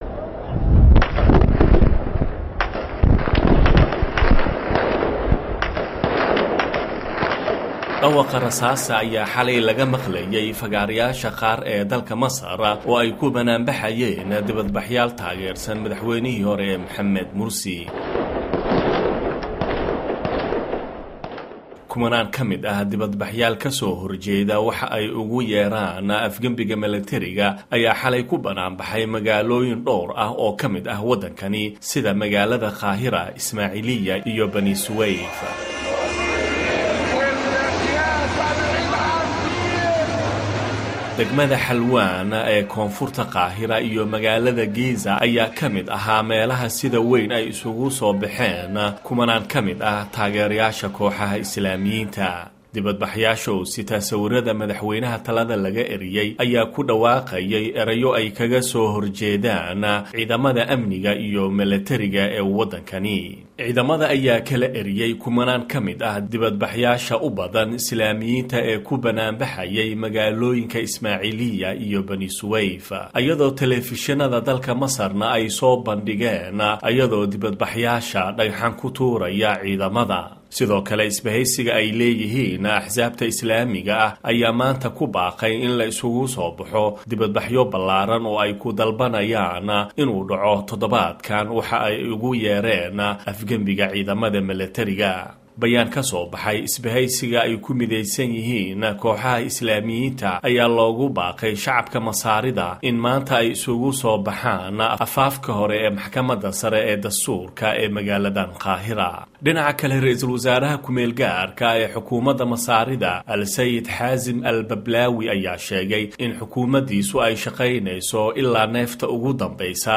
Dhageyso Warbixinta Wararka Masar